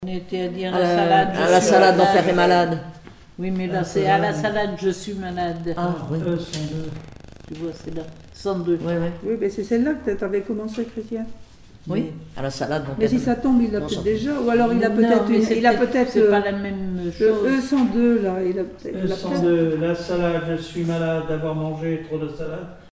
Île-d'Yeu (L')
Patois local
formulette enfantine : amusette
comptines et formulettes enfantines